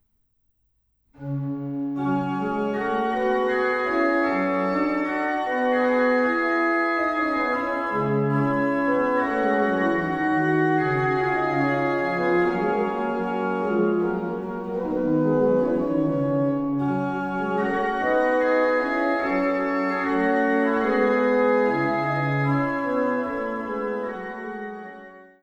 orgue flamand (Cattiaux) de l’église de Beurnevésin